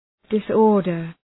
Shkrimi fonetik {dıs’ɔ:rdər}